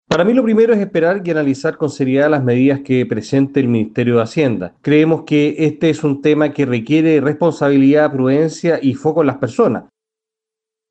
Desde la otra vereda, el diputado republicano Agustín Romero enfatizó en la necesidad de esperar las medidas concretas, las que, según dijo, deberían tener especial foco en las personas.